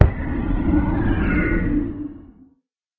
elder_death.ogg